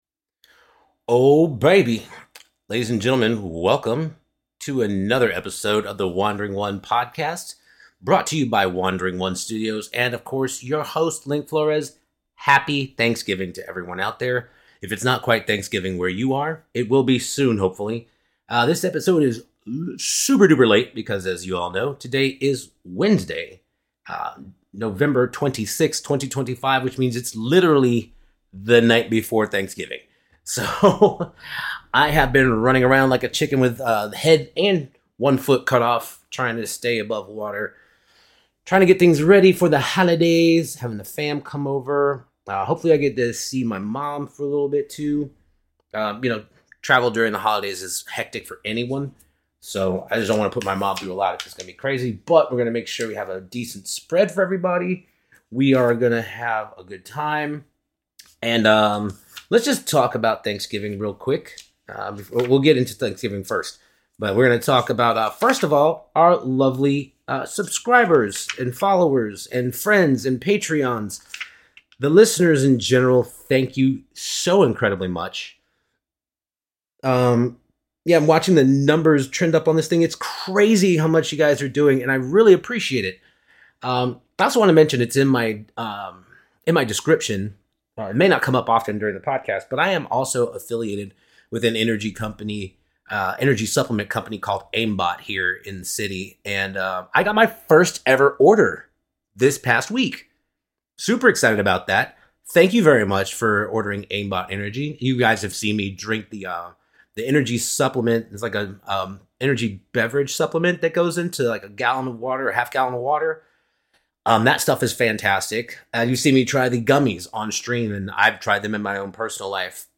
<<< WARNING: May contain adult language and thematic content. Listener discretion is advised. >>>